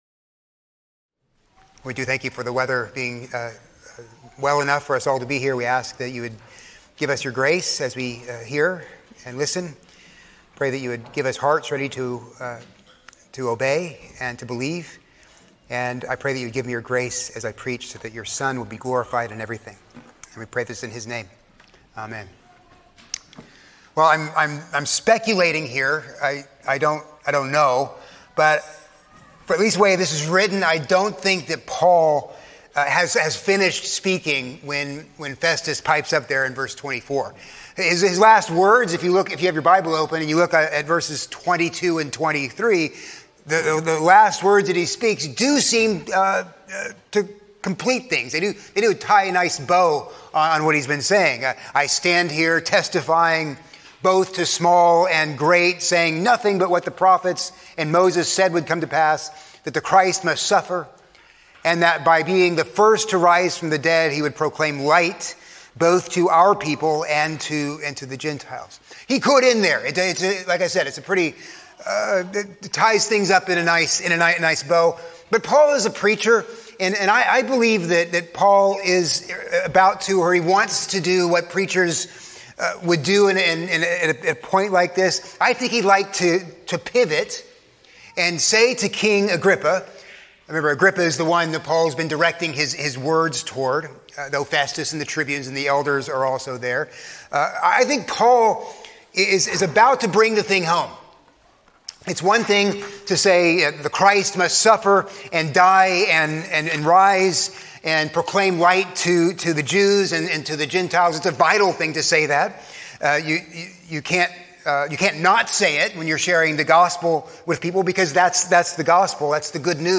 A sermon on Acts 26:24-32